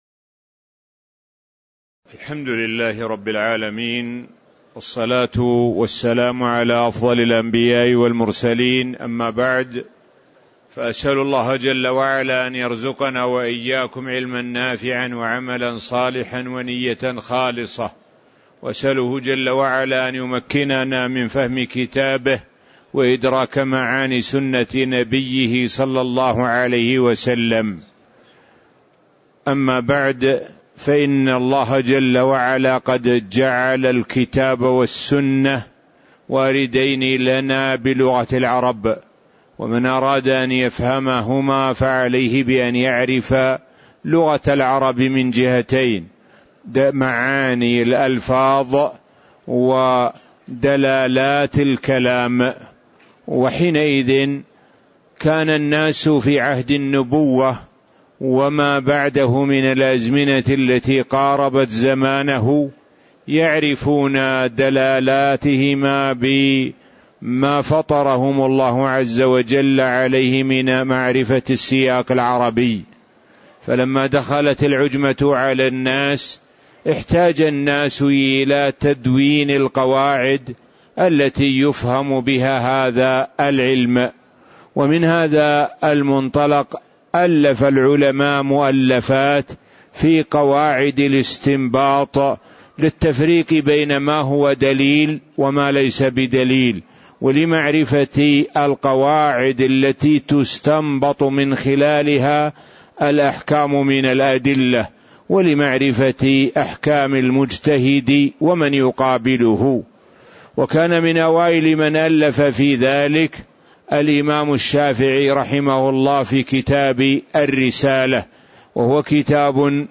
تاريخ النشر ٣ جمادى الأولى ١٤٣٩ هـ المكان: المسجد النبوي الشيخ: معالي الشيخ د. سعد بن ناصر الشثري معالي الشيخ د. سعد بن ناصر الشثري المقدمة (001)